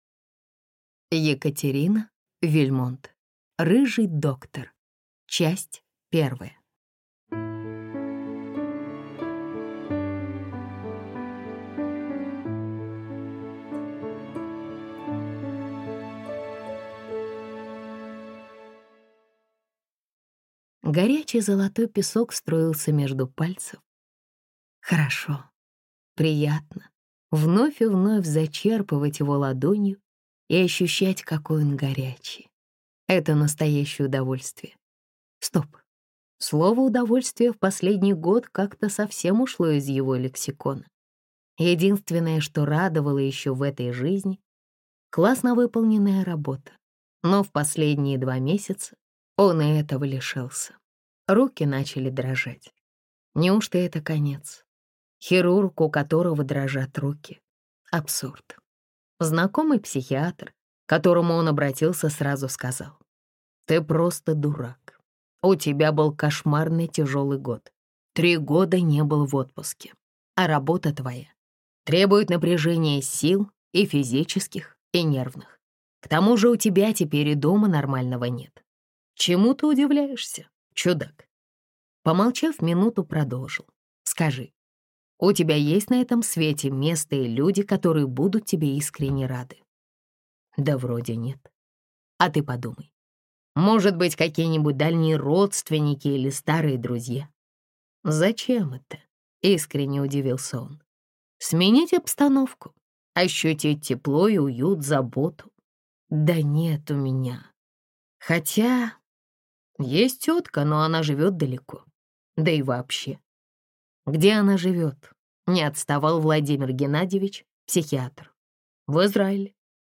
Аудиокнига Рыжий доктор | Библиотека аудиокниг